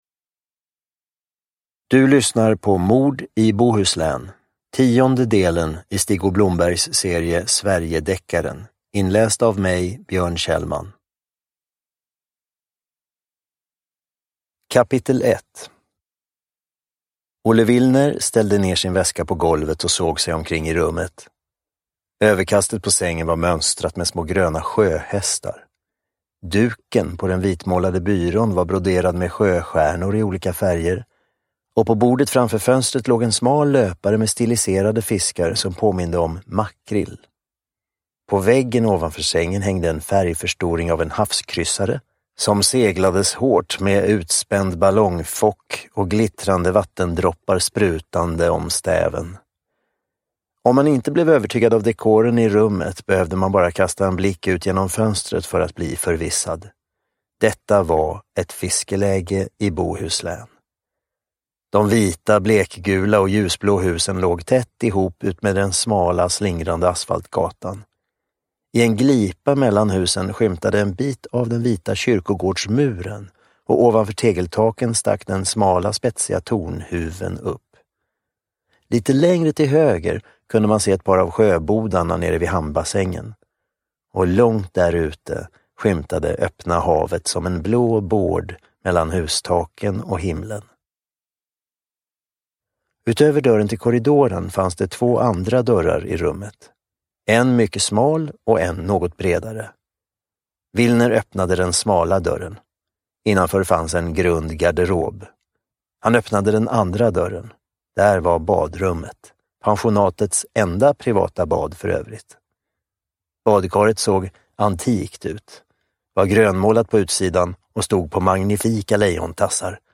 Uppläsare: Björn Kjellman